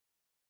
silencehalf.wav